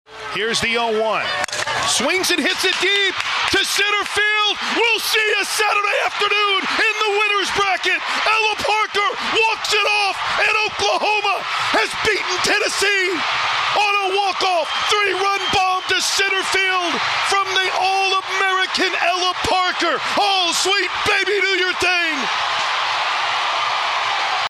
Here is how the game-winner sounded on KPGM.